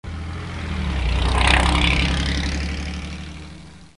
Automobile che passa rumorosa
Rumore d'auto che si avvicina all'ascoltatore e si allontana con marmitta leggermente rumorosa.
LOUDCAR2.mp3